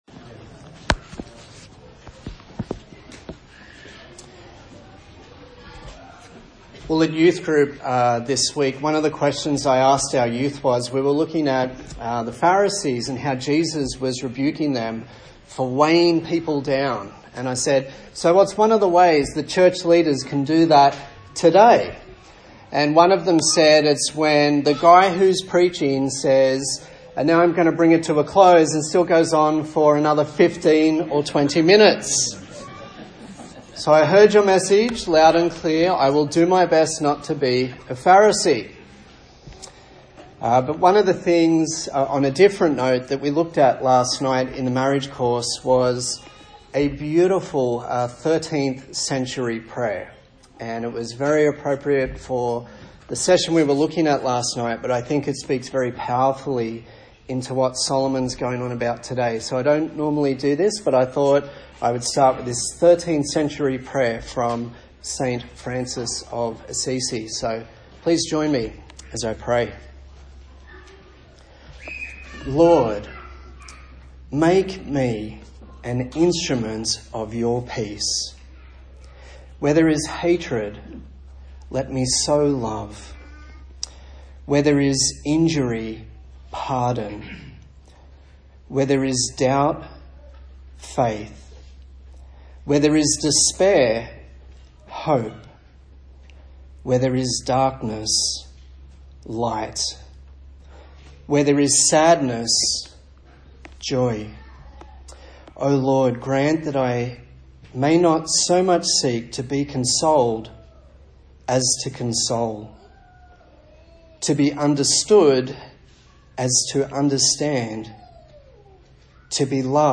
Service Type: Sunday Morning A sermon in the series on the book of Ecclesiastes